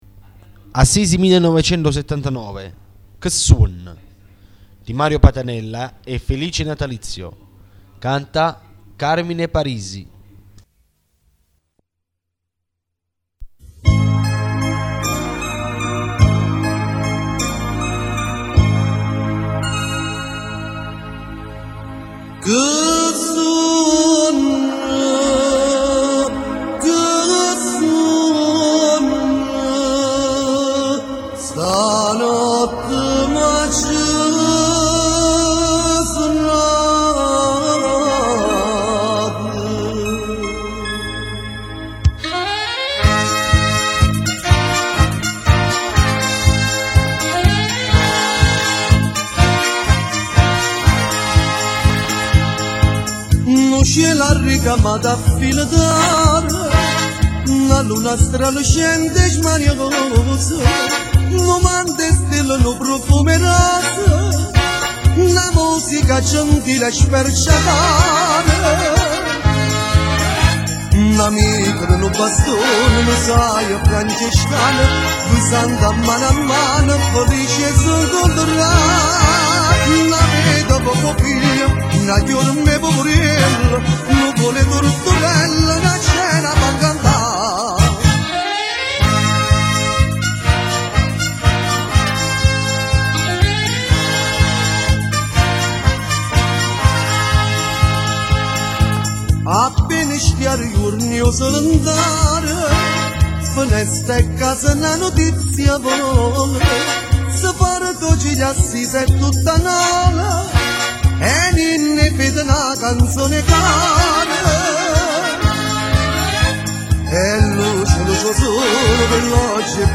Assisi 1979 - Canzoni - Festa Dei Gigli
Canzoni del Giglio ad Assisi 1979 Paranza Nolana Città di Nola